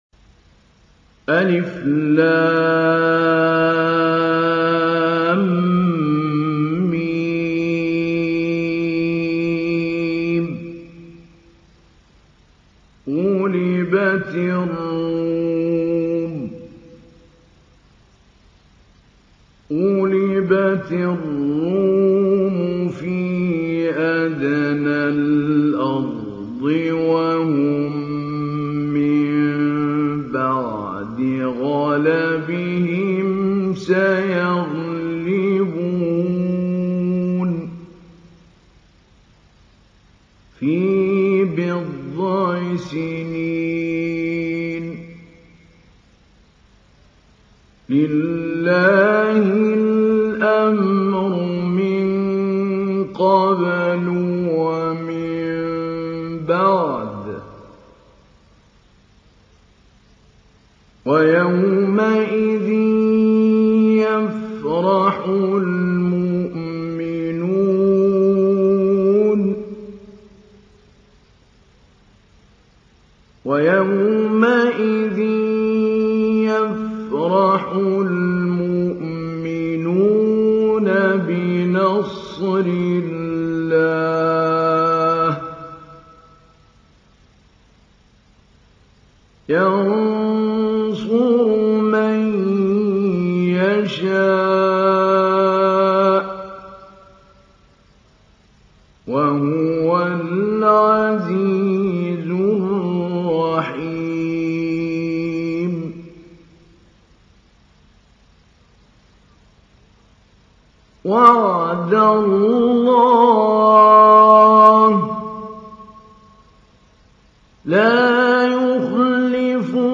تحميل : 30. سورة الروم / القارئ محمود علي البنا / القرآن الكريم / موقع يا حسين